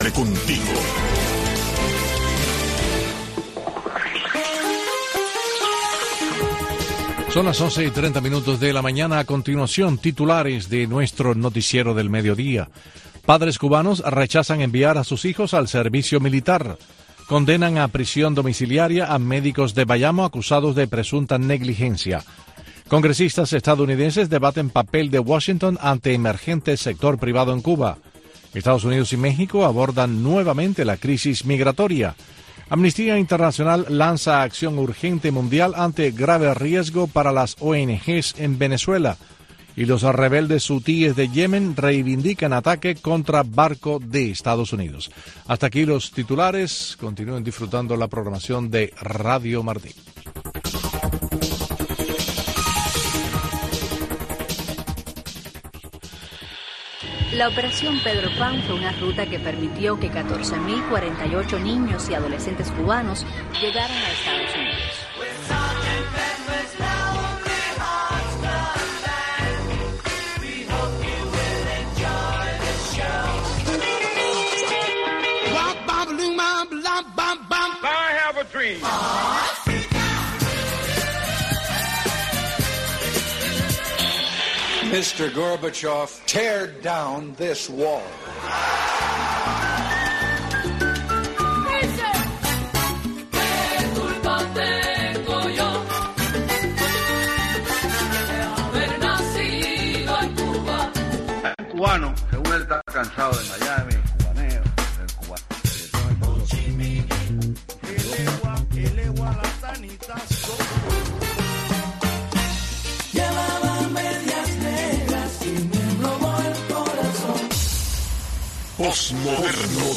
banda de rock